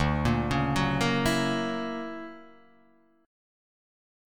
D+M9 chord